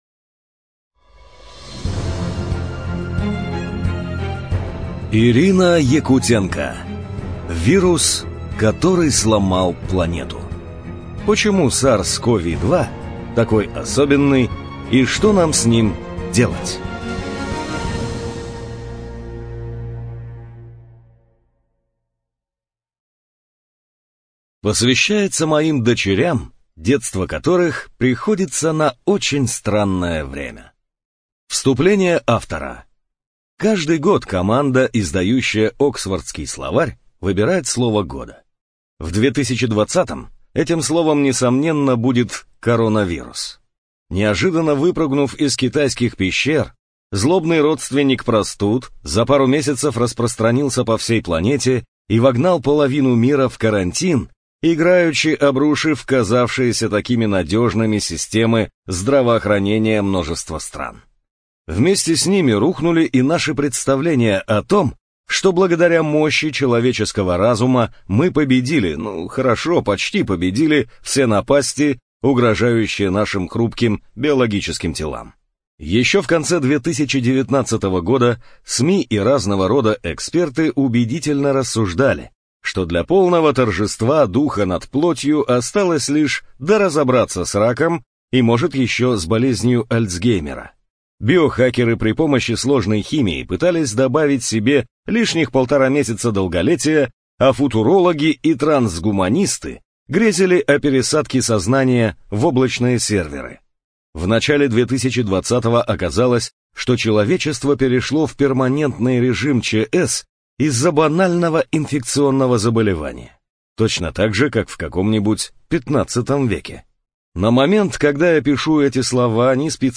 ЖанрНаучно-популярная литература